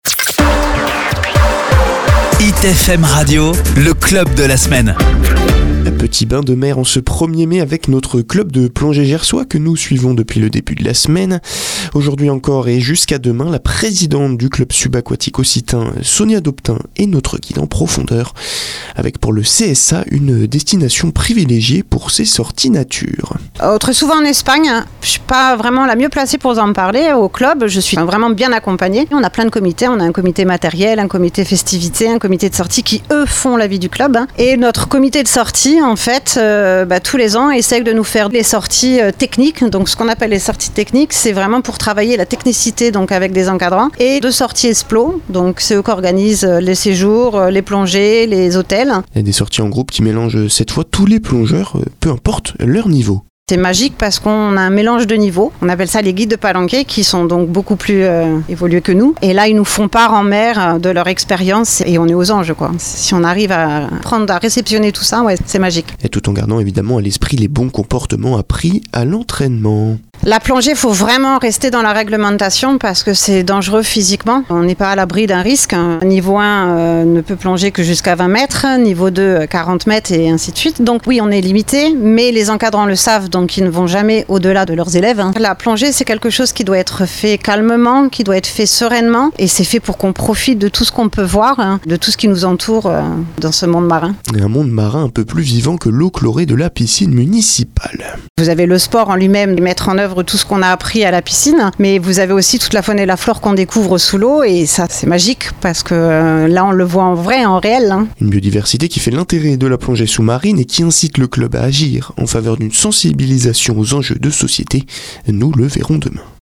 LE CSA PASSE A LA RADIO SUR HIT FM